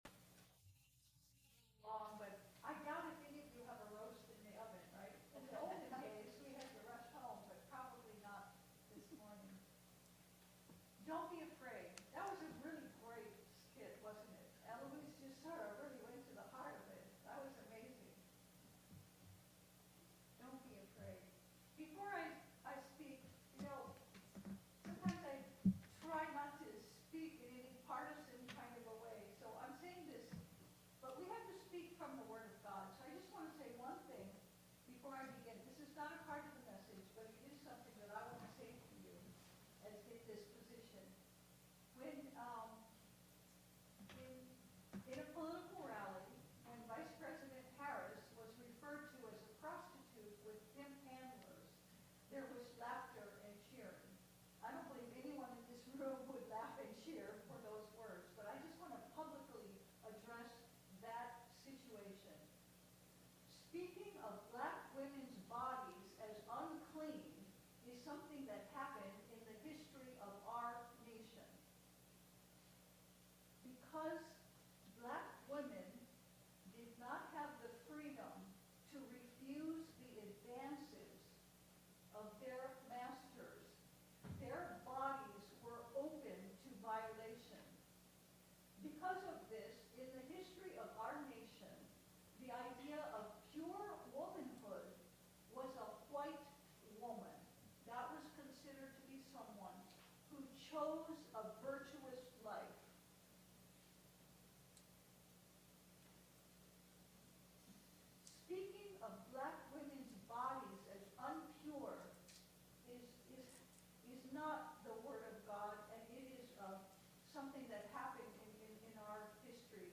1 Kings 17:8-16 Service Type: Sunday Service God will address your heart as well as your circumstances.